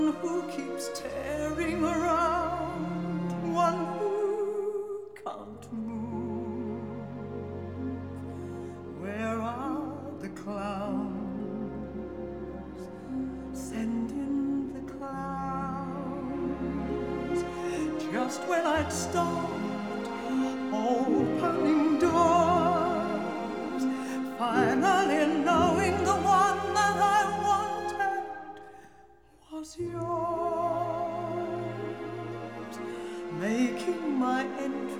Жанр: Джаз / Классика